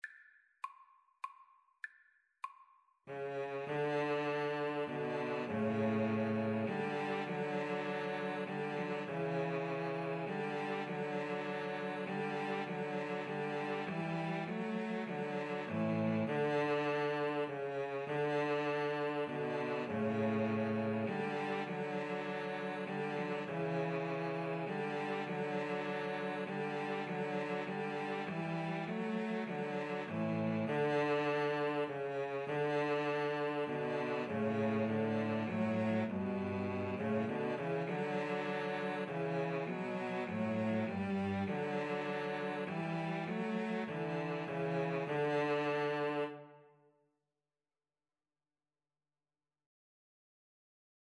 3/4 (View more 3/4 Music)
Cello Trio  (View more Easy Cello Trio Music)